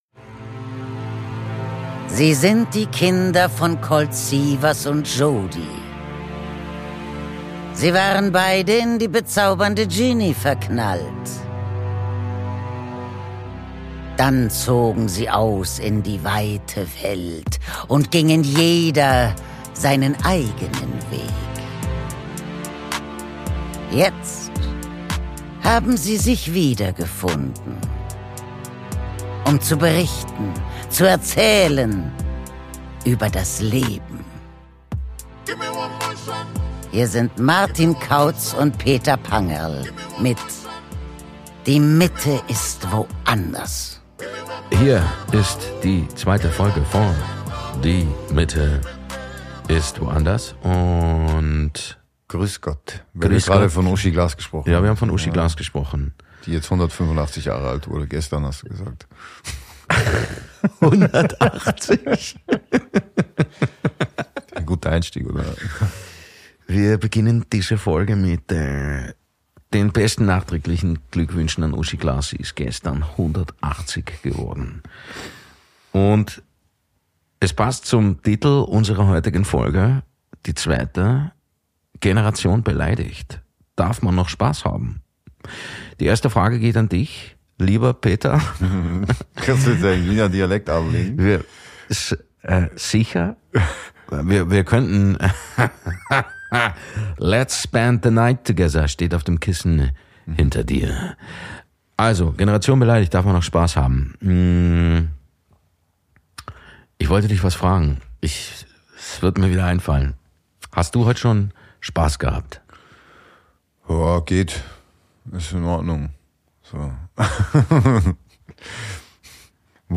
Aus dem 25h Hotel im Wiener Museumsquartier mit viel Charme und knallharten Worten präsenti...